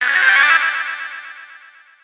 メール音やSMSの通知音。